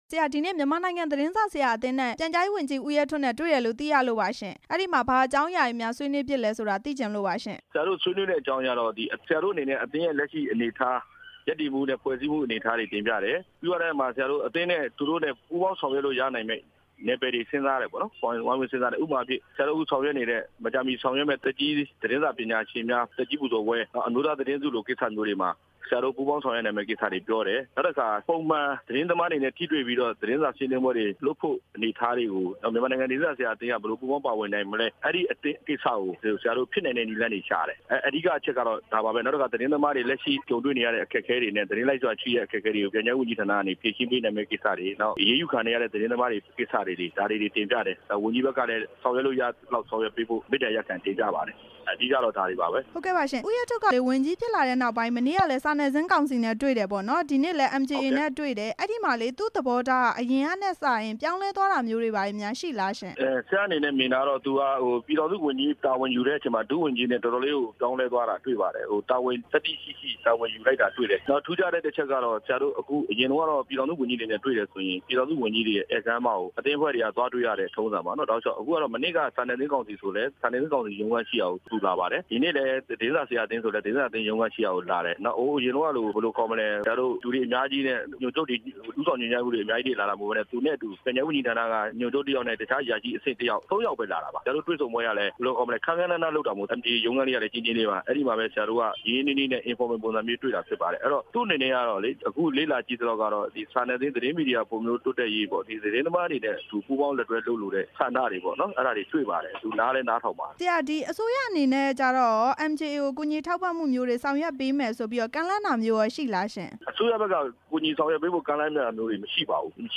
နဲ့ မေးမြန်းချက်